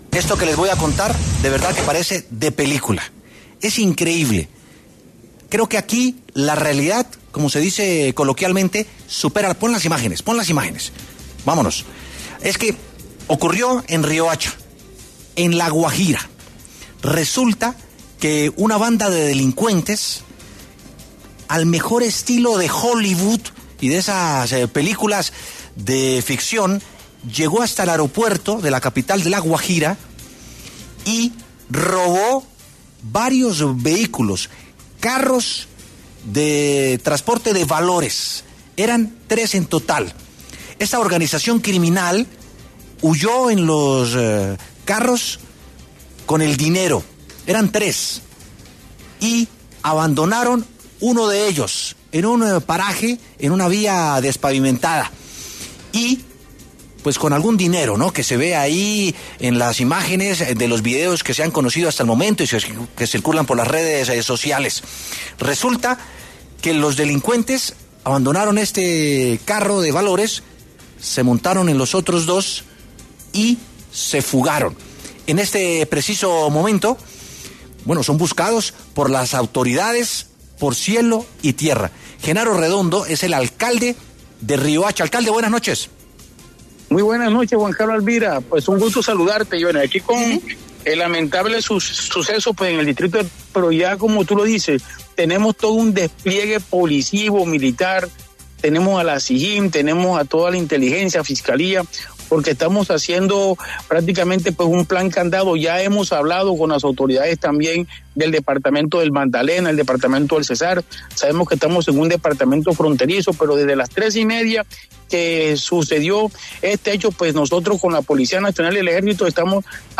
Genaro Redondo, alcalde de Riohacha, pasó por los micrófonos de W Sin Carreta para entregar detalles del robo que se presentó en el aeropuerto Almirante Padilla de Riohacha, donde tres camionetas interceptaron un carro de valores que estaba haciendo descargue de la bodega de un avión de Latam Airlines.